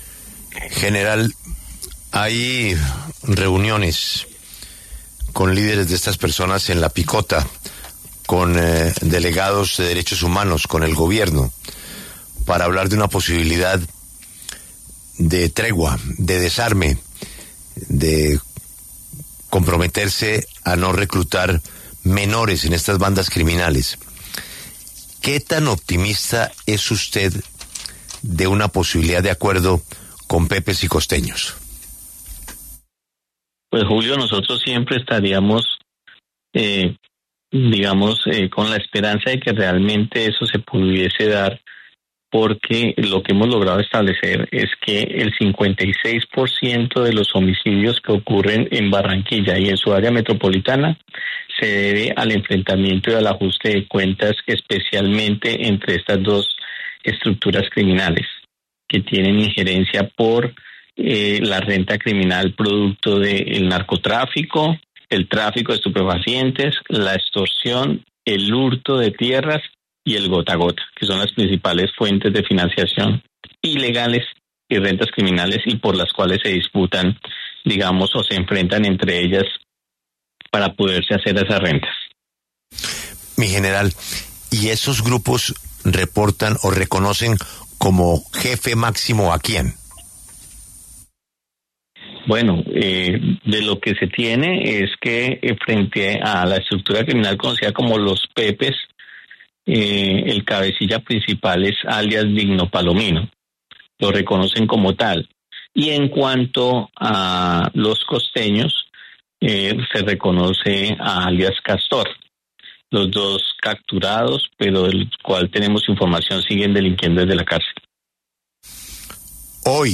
El brigadier general Edwin Urrego, comandante de la Policía Metropolitana de Barranquilla, también se refirió en La W a las conversaciones de paz que se adelantan entre ‘Los Costeños’ y ‘Los Pepes’.